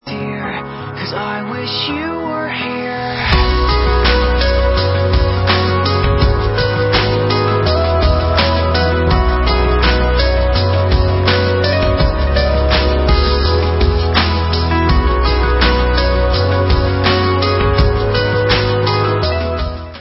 disco, evropský elektropop a osmdesátkový synthpop
Čerstvý, zasněný pop pro každého!